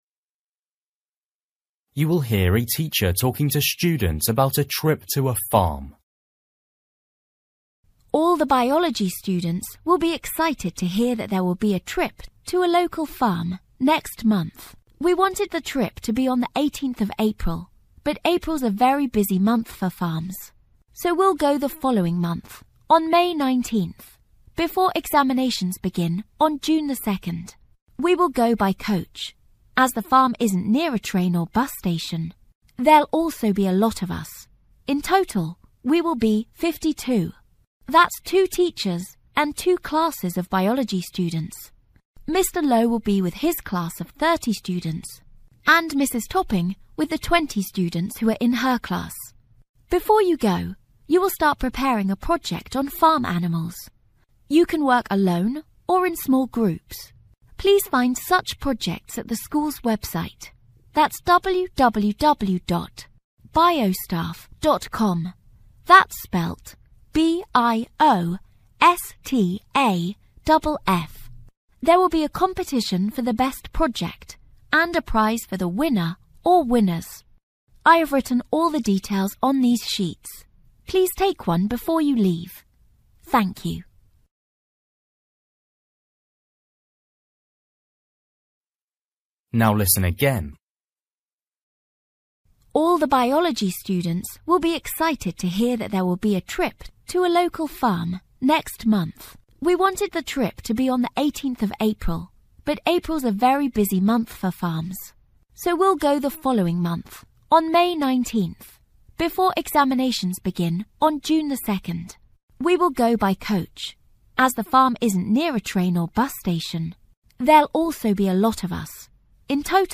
You will hear a teacher talking to students about a trip to a farm.